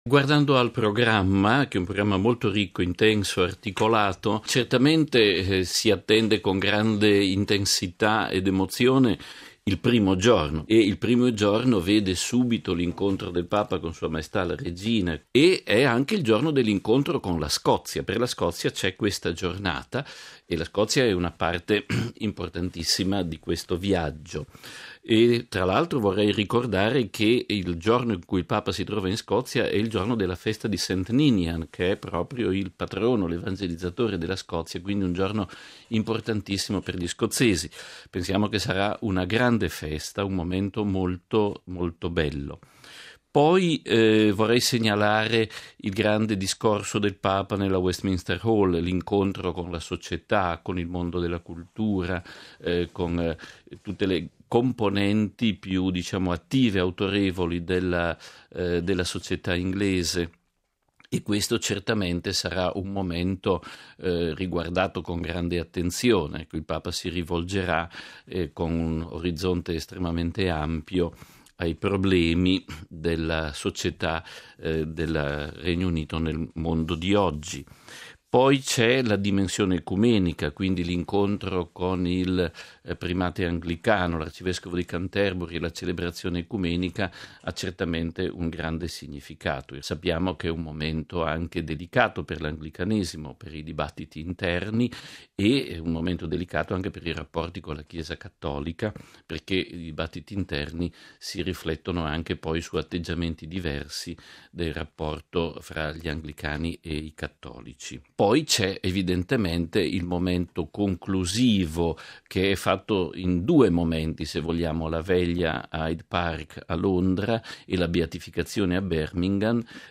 ◊ Udienza generale, stamani, nel cortile del Palazzo Apostolico di Castel Gandolfo. Il Papa ha lanciato un accorato appello per le popolazioni alluvionate del Pakistan.